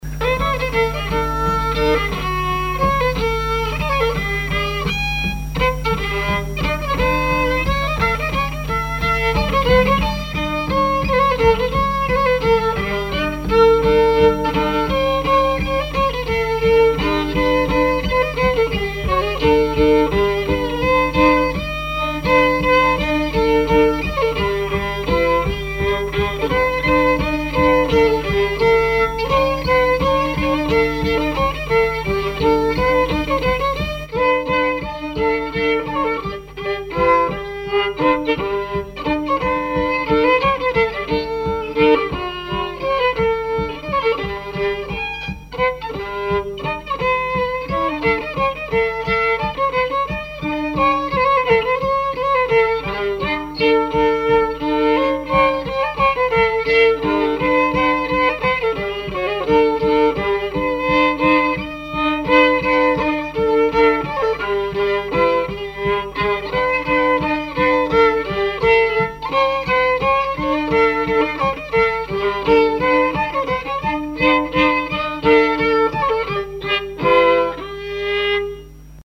danse : java
collectif de musiciens pour une animation à Sigournais
Pièce musicale inédite